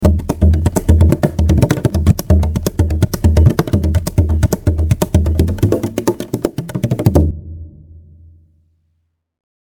Bohdran solo ringtone